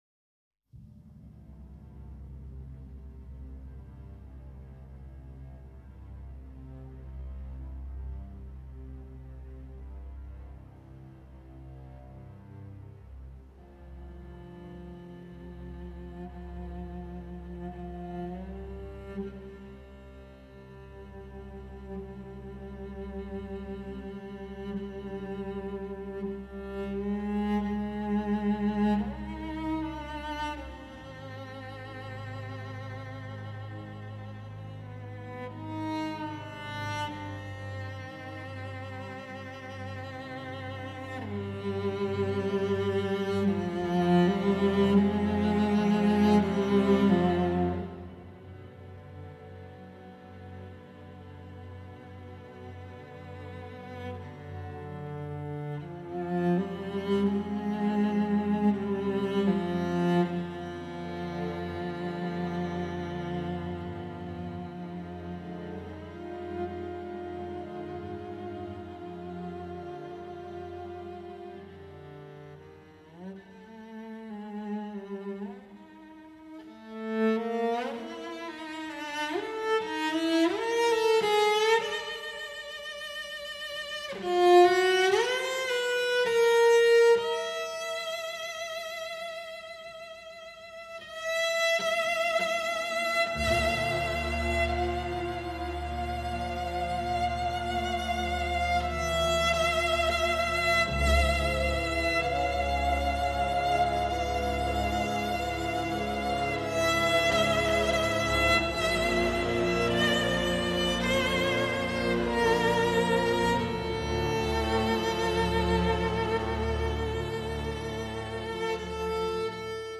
Cello